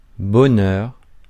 Ääntäminen
Synonyymit pied ataraxie félicité Ääntäminen France: IPA: [bɔ.nœʁ] Haettu sana löytyi näillä lähdekielillä: ranska Käännös 1. щастие {n} (štástie) Suku: m .